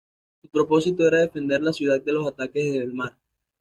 Pronounced as (IPA) /defenˈdeɾ/